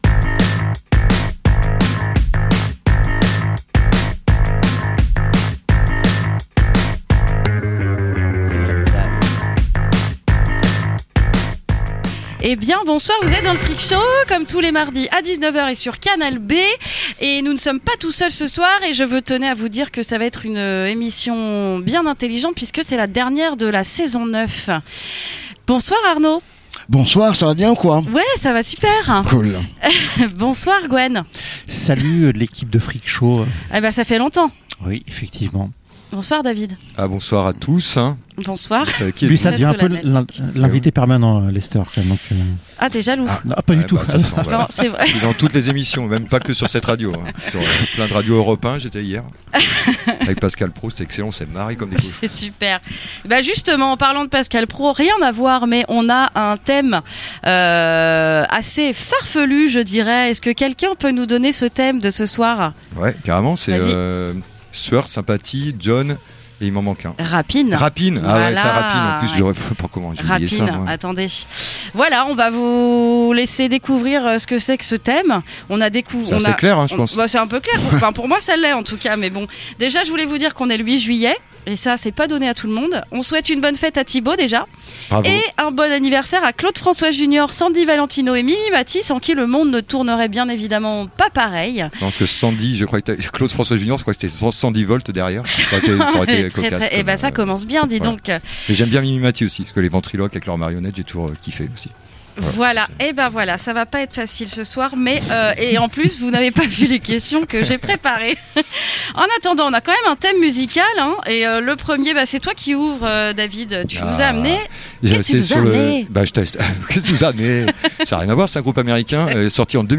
Bouillon de culture, le Freak Show est un rendez-vous philanthrope qui tend l'oreille et le micro à des invités culturels, entremêlé de nouveautés musicales , de zèle et d'interview décalées.